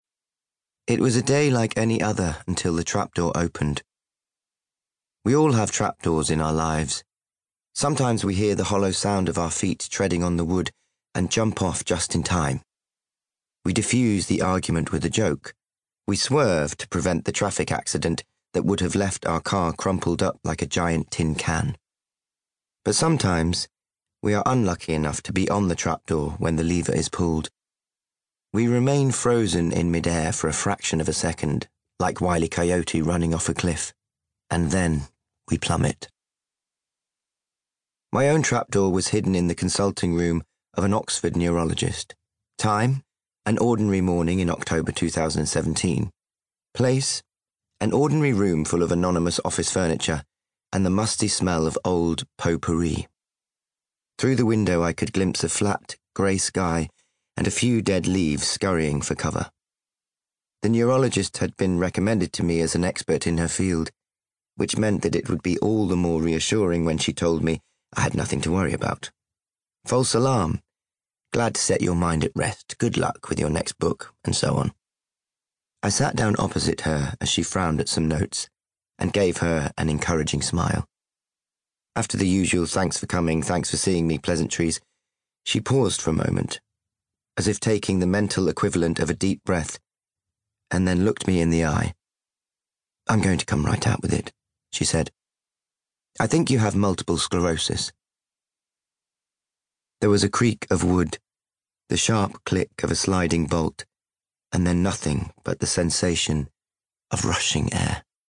30/40's Light Midlands/US Comedic/Gentle/Expressive
Character Showreel